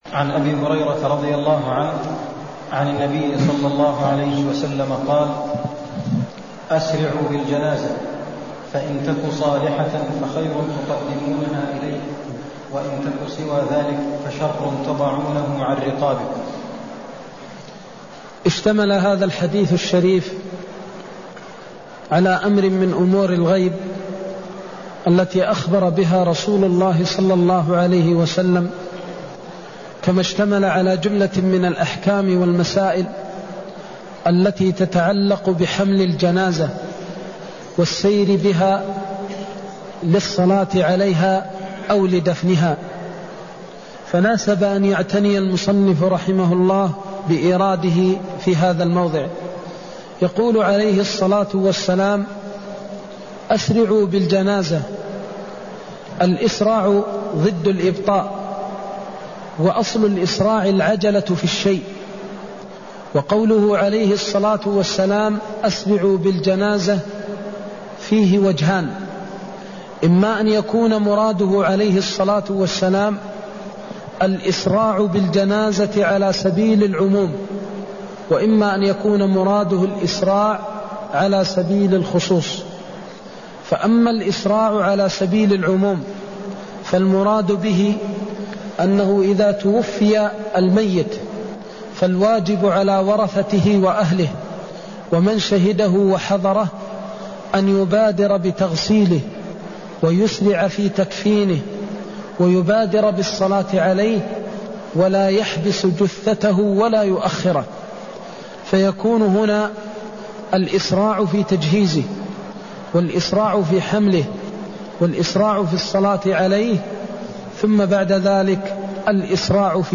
المكان: المسجد النبوي الشيخ: فضيلة الشيخ د. محمد بن محمد المختار فضيلة الشيخ د. محمد بن محمد المختار استحباب الإسراع في الجنازة بعد الصلاة عليها (156)